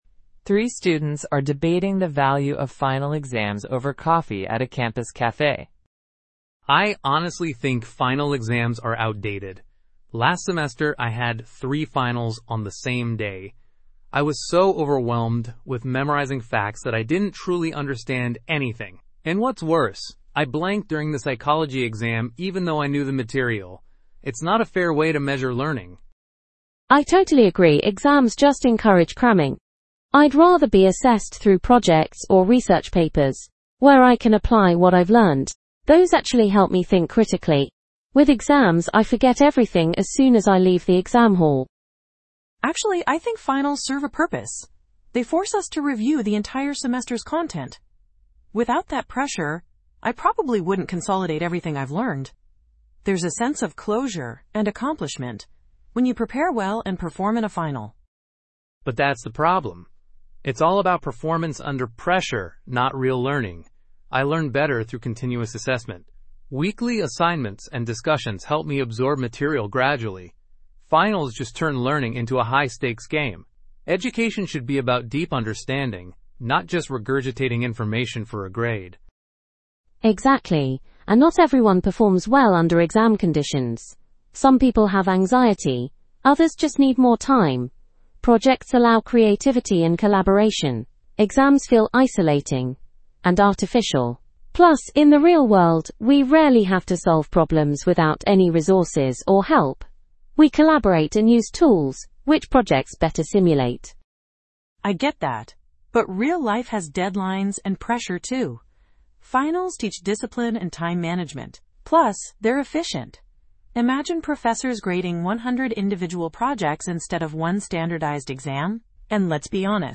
PTE Summarize Group Discussion – The Final Exam